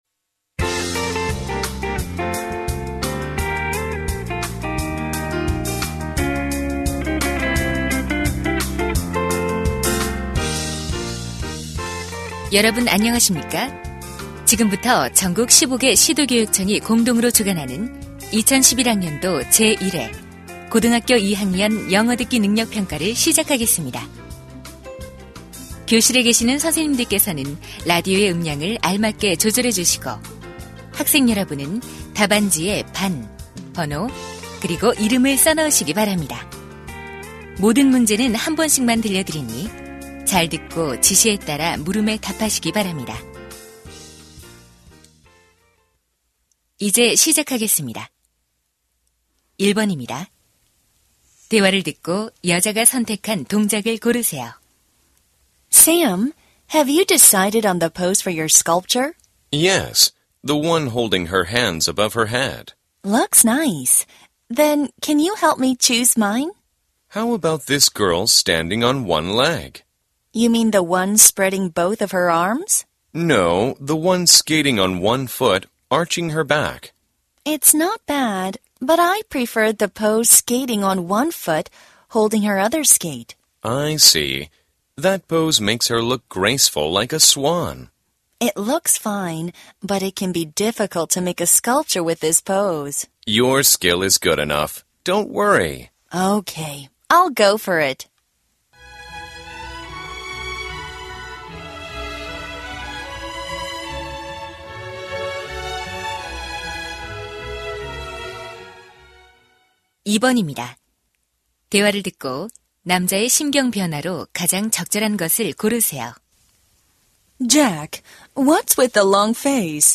2011년 제1회 고2 영어 듣기능력평가 문제 듣기 대본 - 듣기/말하기 - 인조이 잉글리시